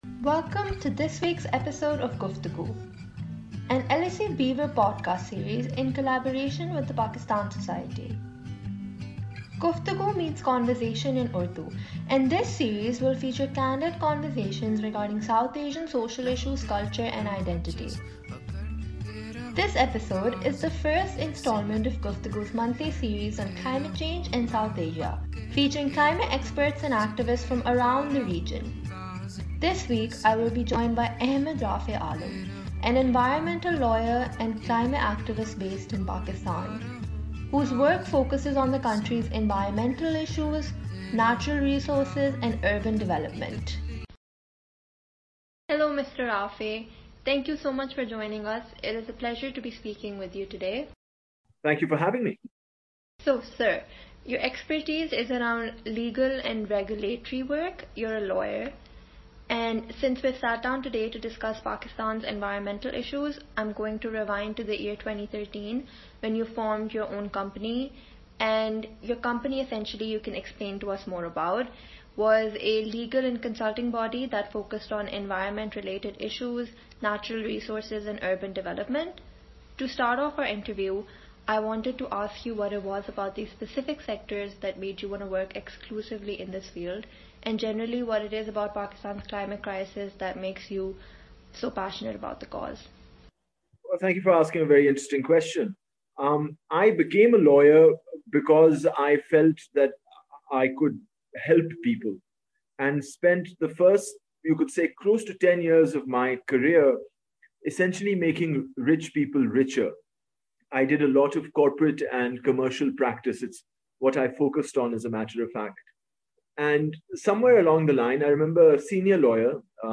In this series, we sit down with climate experts and activists from around the region to explore the road ahead for South Asia in its climate challenge.